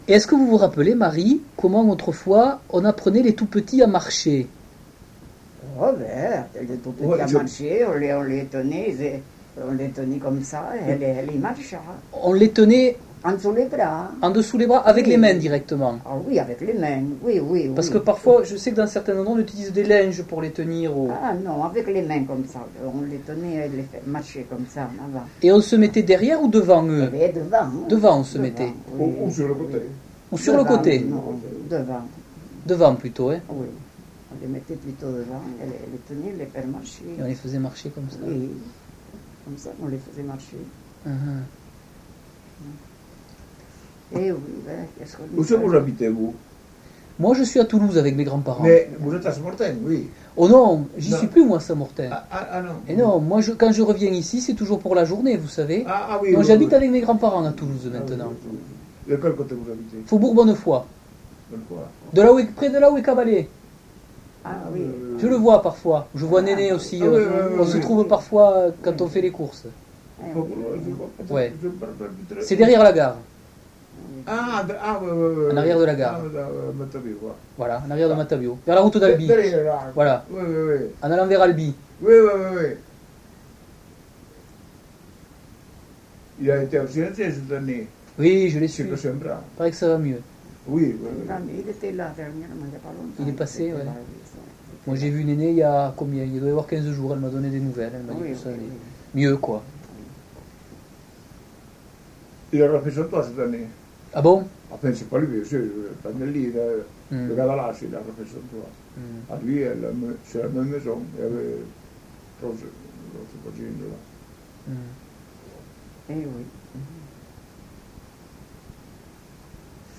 Lieu : Ayet (lieu-dit)
Genre : témoignage thématique